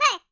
Sfx Player Boulder Toss Sound Effect
sfx-player-boulder-toss.mp3